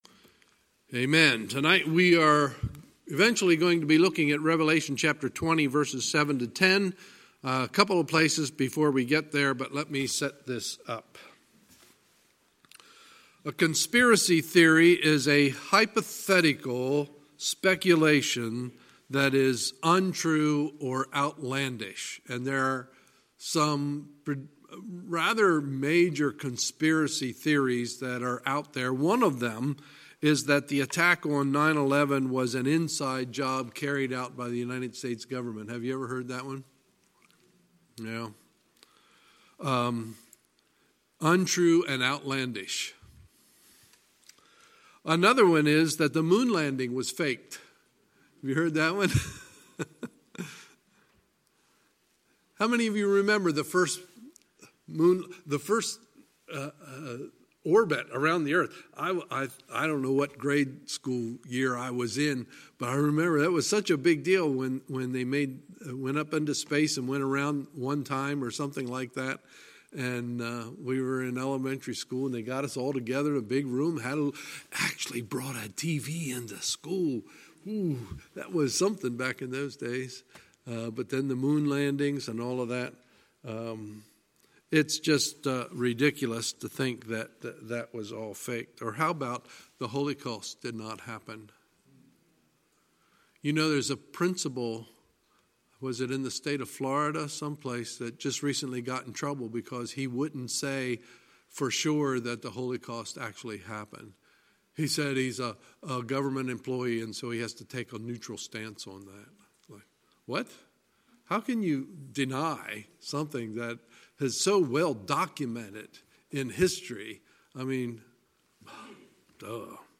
Sunday, November 3, 2019 – Sunday Evening Service